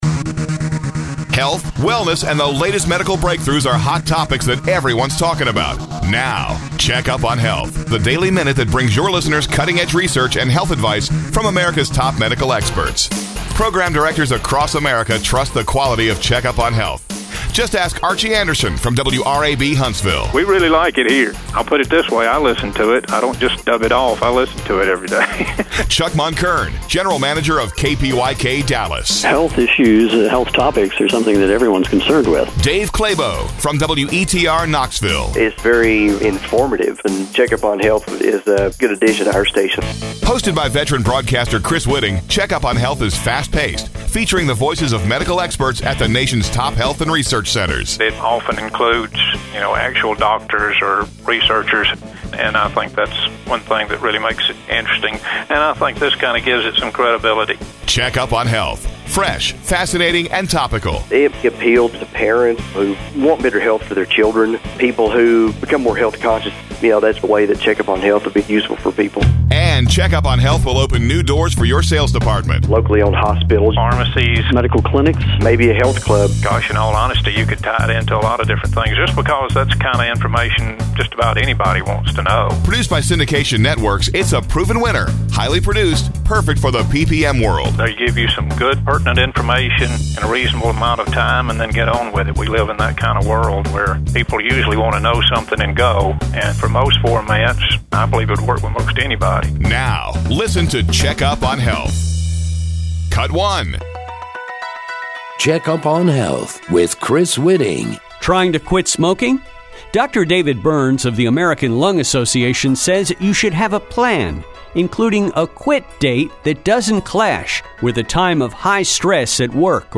CHECK UP ON HEALTH presents health breakthroughs and fast-paced interviews with top medical experts in a daily 60 second format.